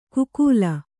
♪ kukūla